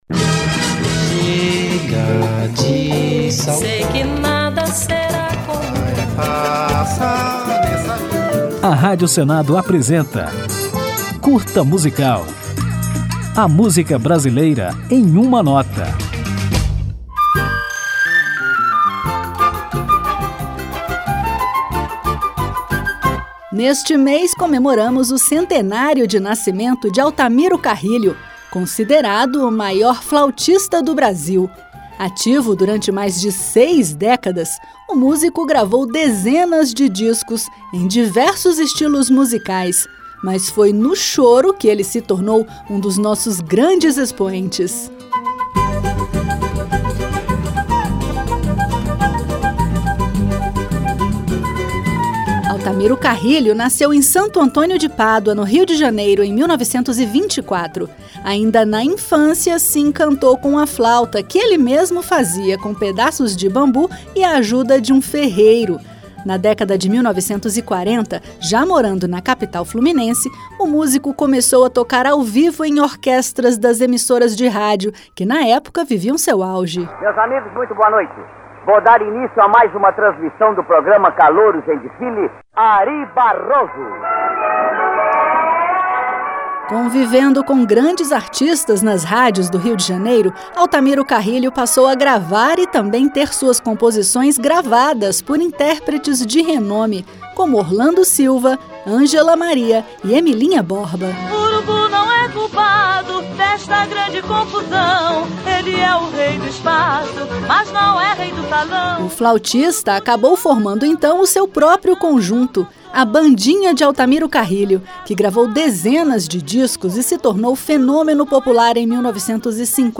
Depois de conhecer um pouco da história do músico, você vai ouvir Altamiro Carrilho no choro Quem É Bom Já Nasce Feito.